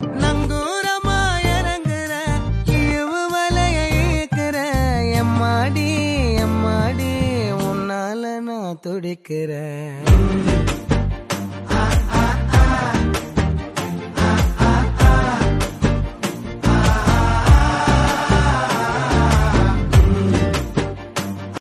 Tamil song